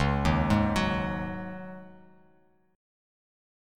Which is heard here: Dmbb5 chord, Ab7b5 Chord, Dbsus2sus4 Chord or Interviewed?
Dbsus2sus4 Chord